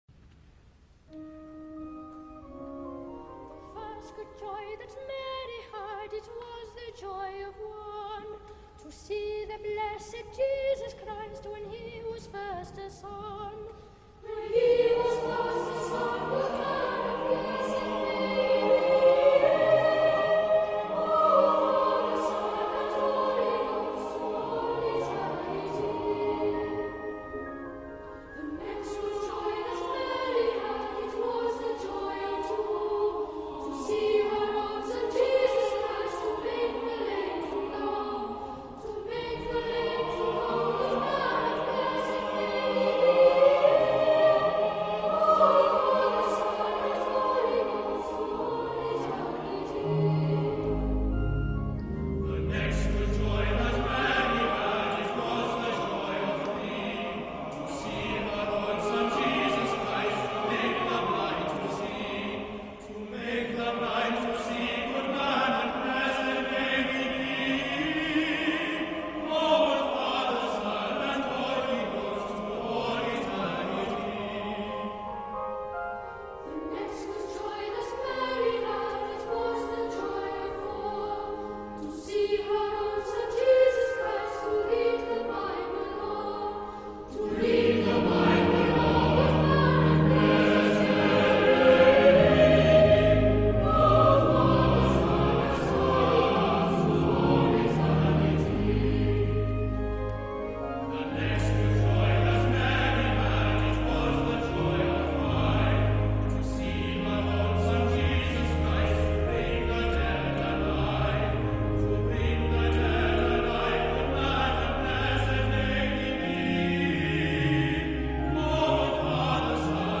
choir.mp3